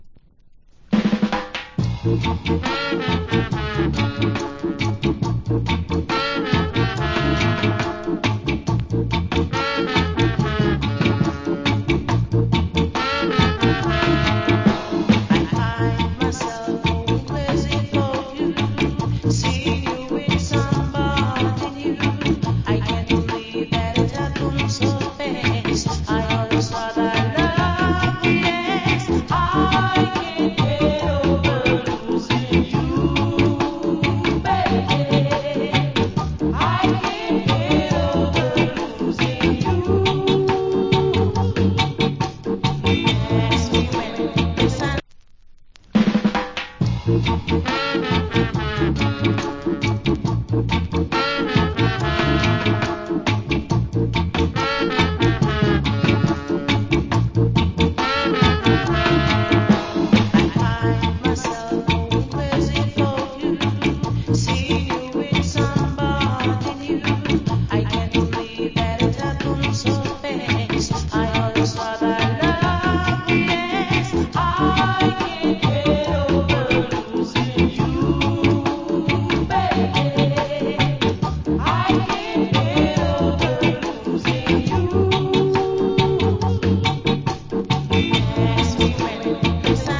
Cool Female Early Reggae Vocal.